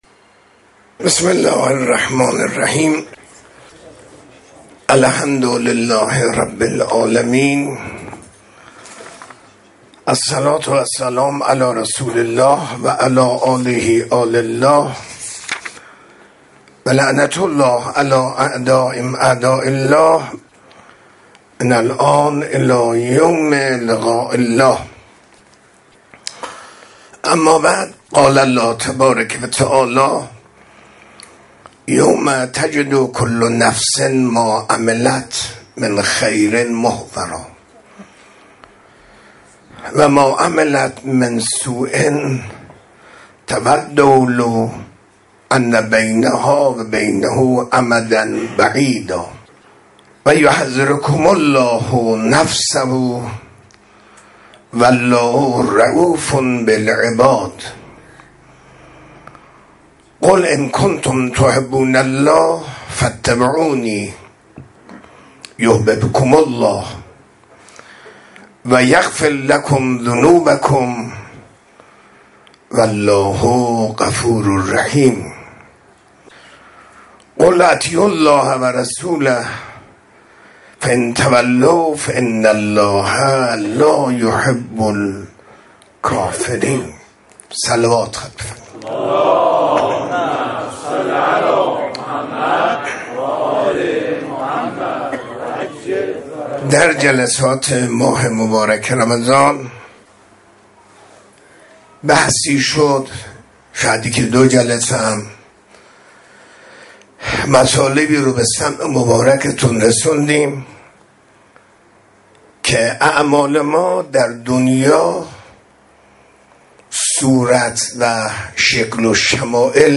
منبر 23 آبان 1403، منزل استاد